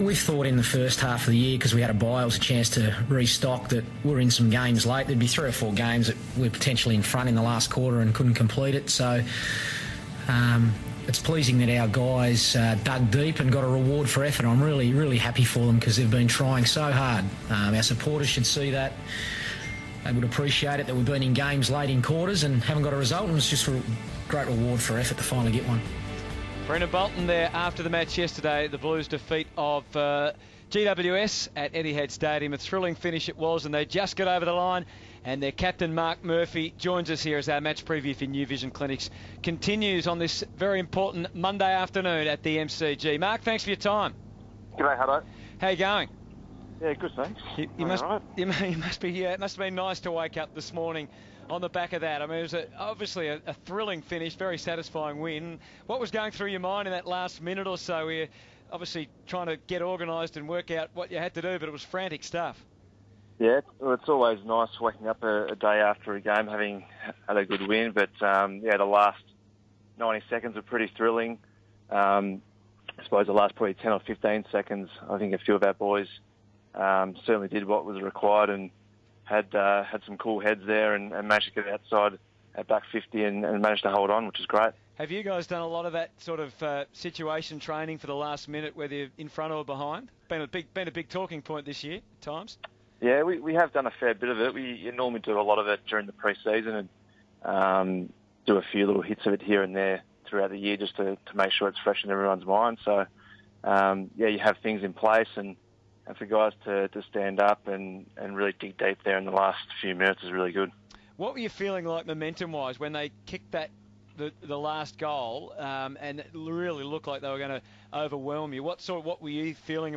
Carlton captain Marc Murphy reviews the Blues' thrilling victory over GWS on SEN 1116.